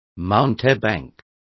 Complete with pronunciation of the translation of mountebank.